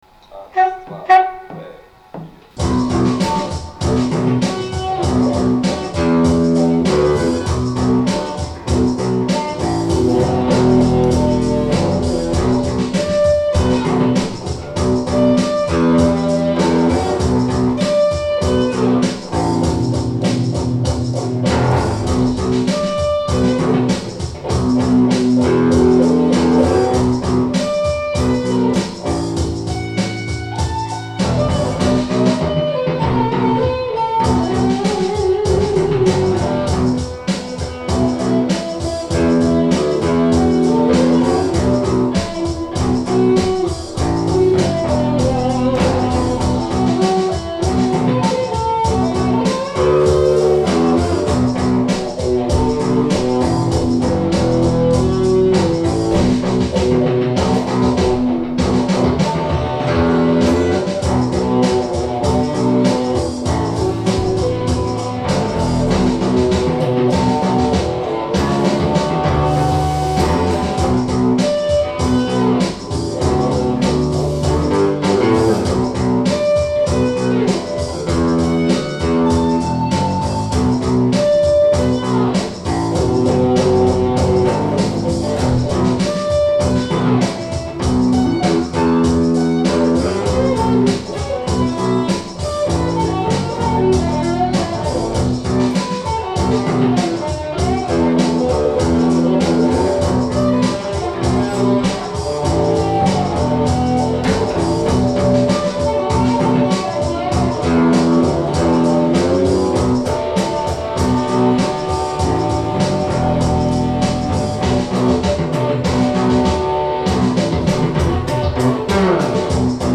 e-bass
guitar
voc, perc.
perc., sax, flute
tuba, microsynth
drums, perc.
Cut from standard cassette-material, live as recorded